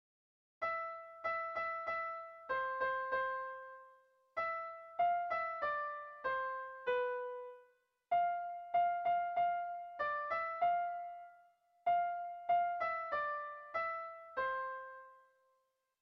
Irrizkoa
Dima < Arratia-Nerbioi < Bizkaia < Euskal Herria
Lauko txikia (hg) / Bi puntuko txikia (ip)
AB